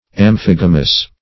Search Result for " amphigamous" : The Collaborative International Dictionary of English v.0.48: Amphigamous \Am*phig"a*mous\, a. [Gr.